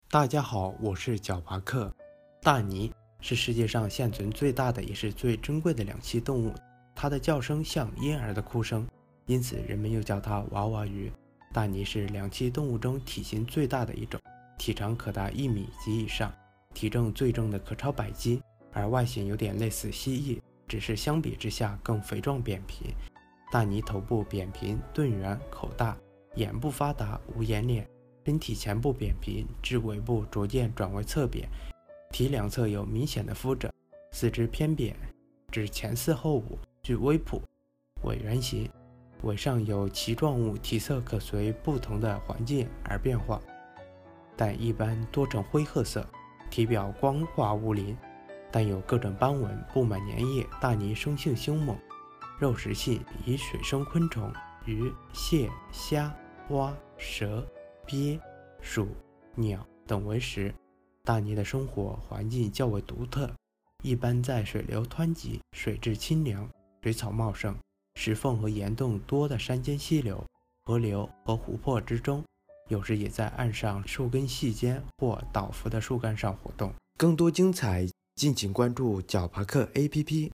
大鲵-----呼呼呼～～～